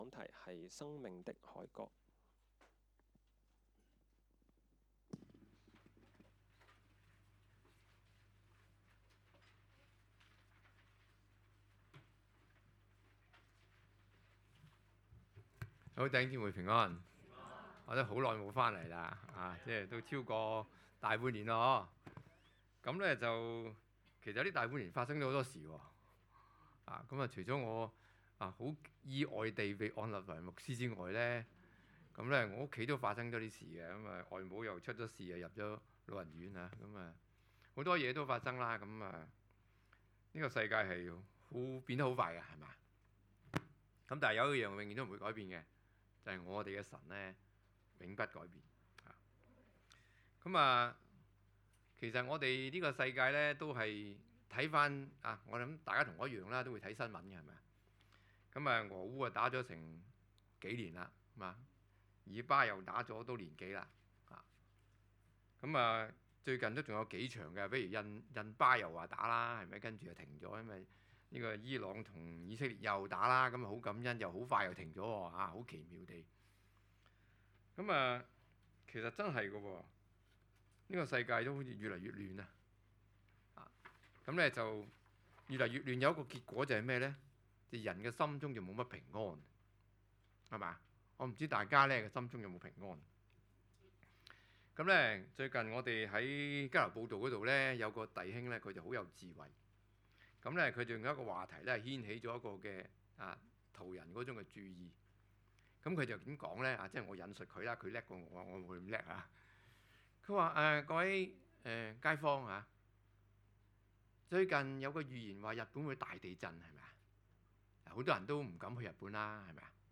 講道 ：生命的凱歌 讀經 : 詩篇 96:1-13